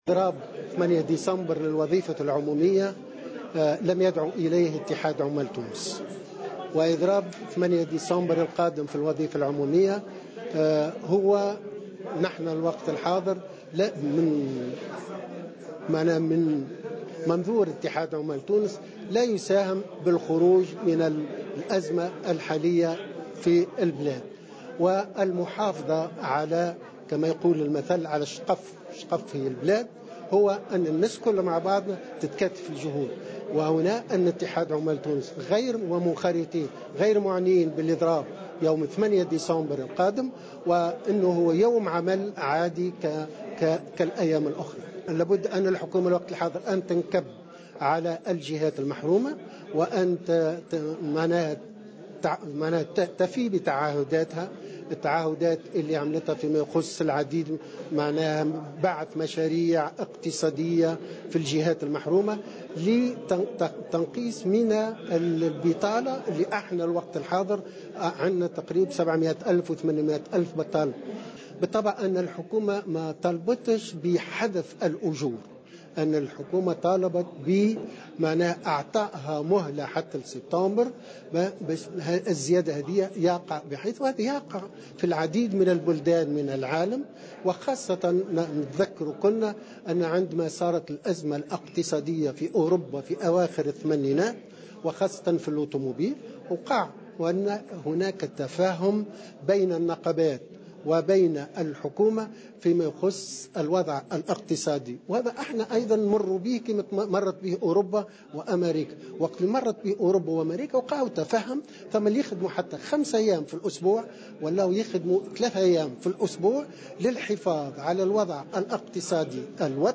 في تصريح لمراسل "الجوهرة أف أم" على هامش اجتماع دوري للهيئة المركزية والكتاب العامين للاتحادات الجهوية لاتحاد عمال تونس